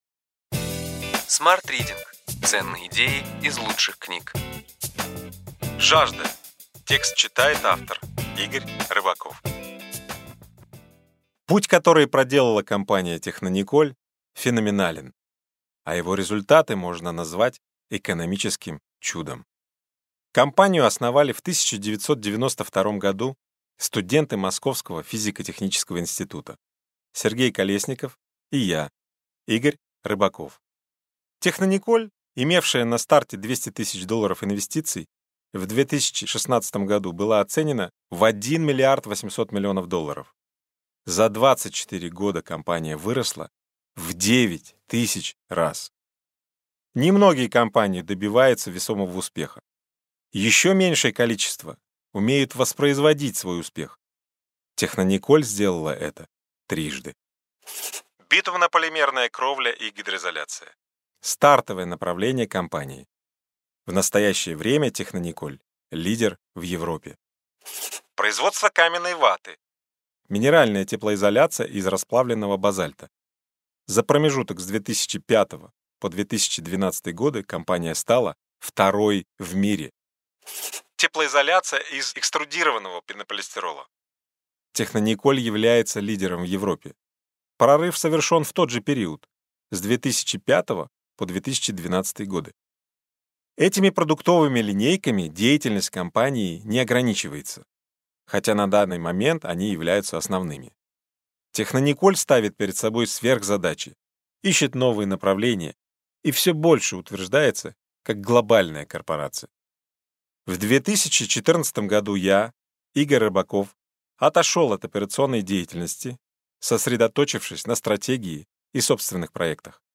Аудиокнига Ключевые идеи книги: Жажда.